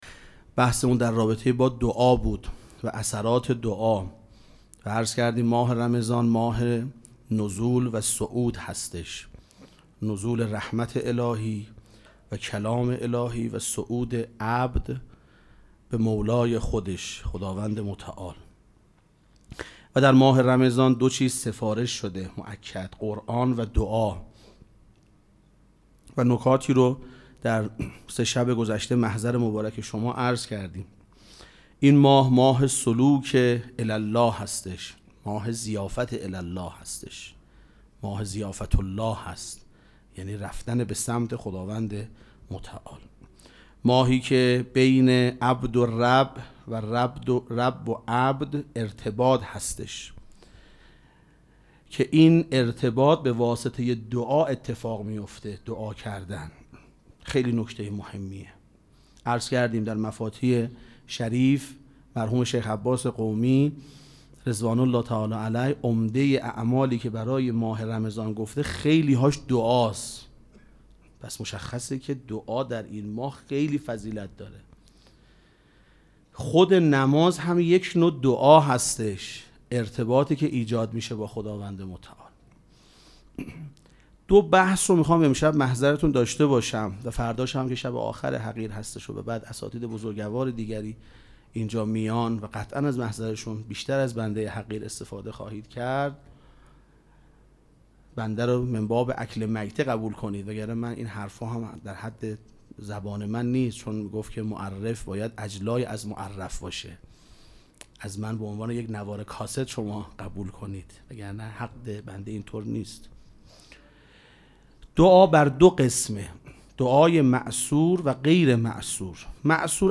سخنرانی
مراسم مناجات شب چهارم ماه مبارک رمضان سه‌شنبه ۱۴ اسفند ماه ۱۴۰۳ | ۳ رمضان ۱۴۴۶ حسینیه ریحانه الحسین سلام الله علیها